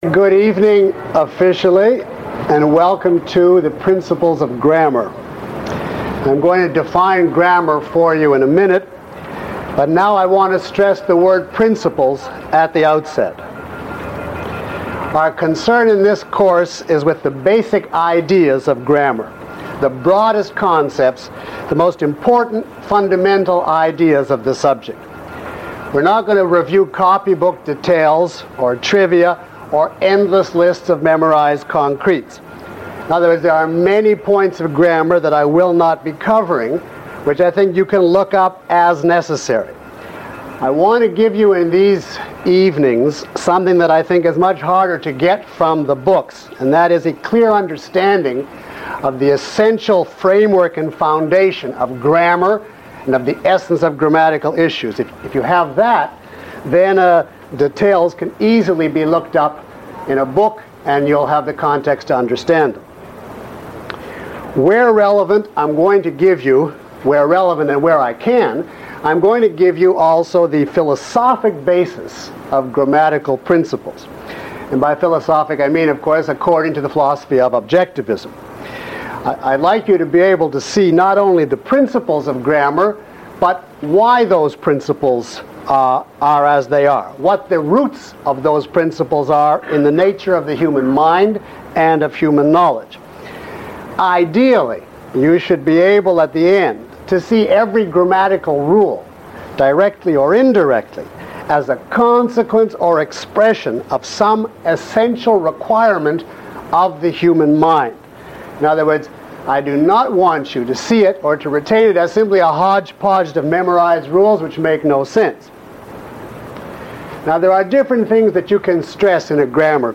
Lecture 01 - Principles of Grammar.mp3